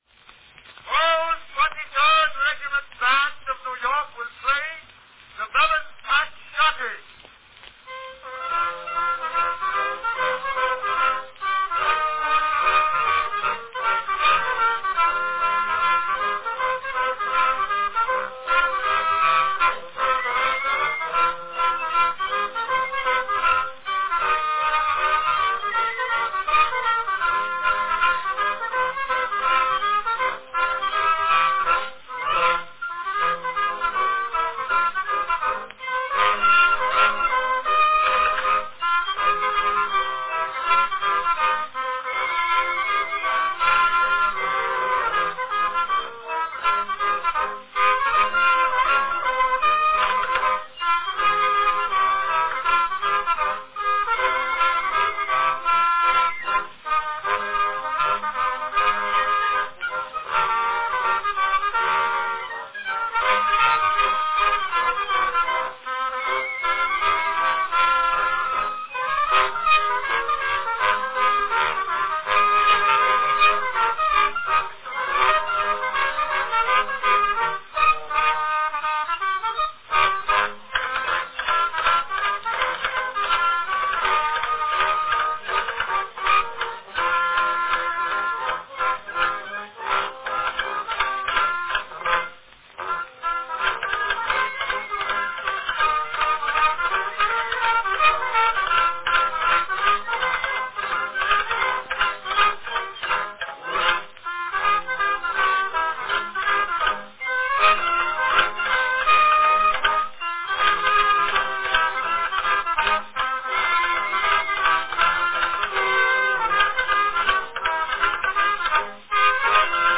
Cylinder of the Month
A snappy band number from 1895, The Melon Patch Schottische, played by the 23rd Regiment Band of New York.
Category Band
Performed by 23rd Regiment Band of New York
Here, the mention of New York points to that regional company, the Metropolitan Phonograph Company, being the source; the sound is consistent with 1895 material; the cylinder type fits in the 1894-1897 range.